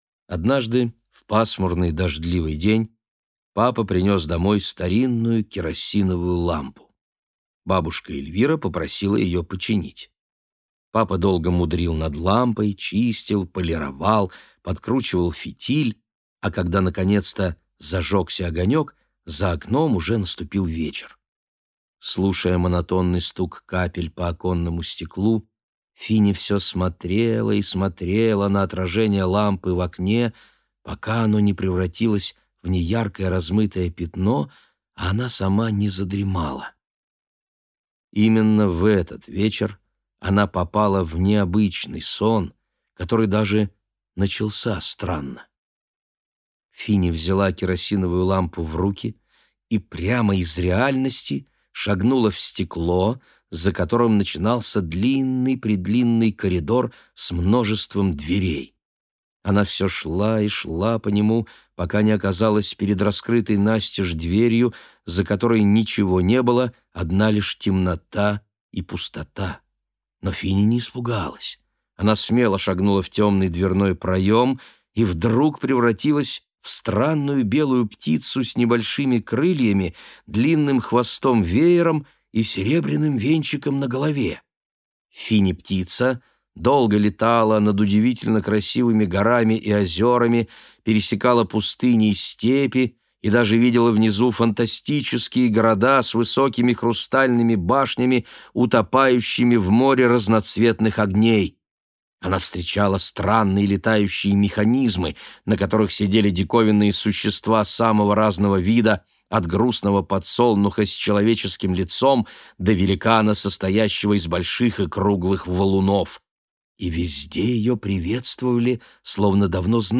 Аудиокнига Финиста. Сонный дом | Библиотека аудиокниг